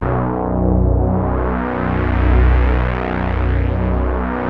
JUP.8 B2   2.wav